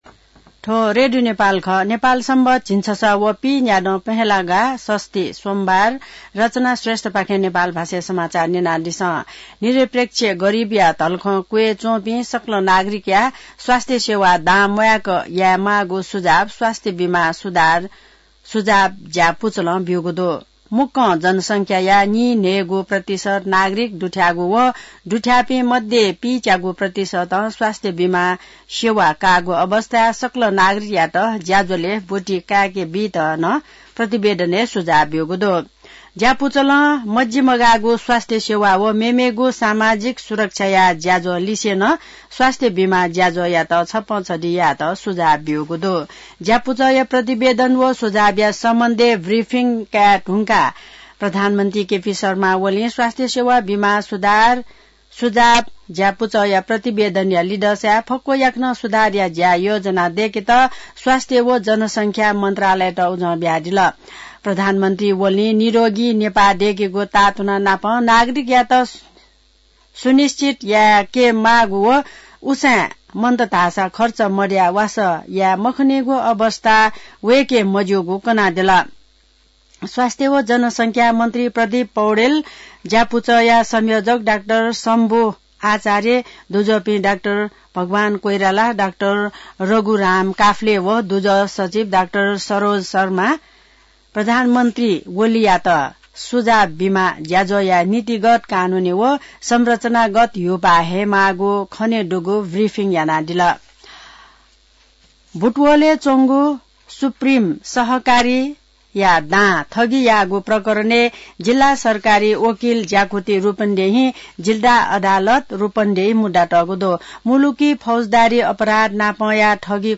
नेपाल भाषामा समाचार : ८ माघ , २०८१